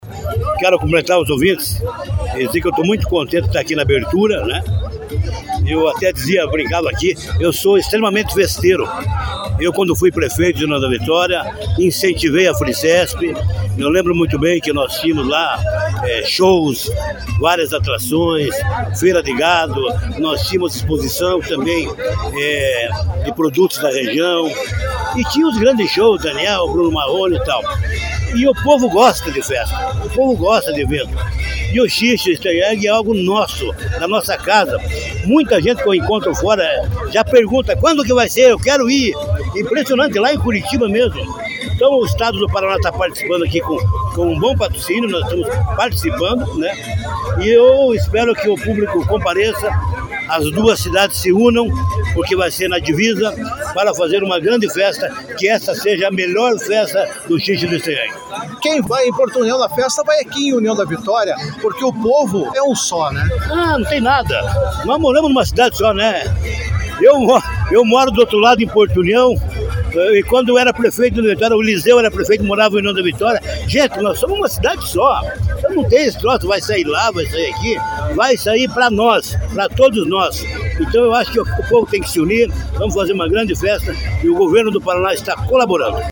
A solenidade de abertura ocorreu em frente ao Clube Apolo, reunindo autoridades, organizadores e a comunidade local.
Durante a cerimônia, o deputado estadual Hussein Bakri também marcou presença e destacou o espírito festivo da comunidade://